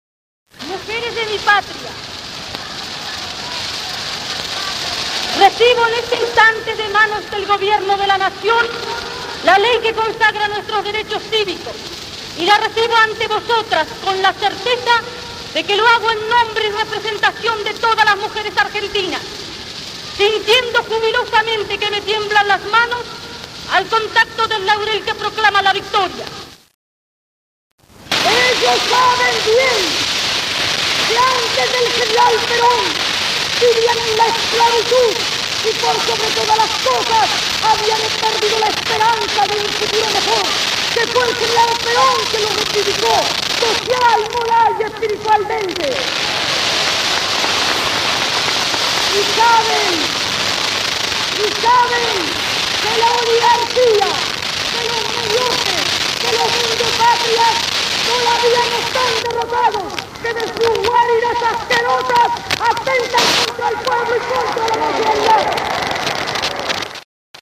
Discurso de Eva Perón (voto femenino) (1949)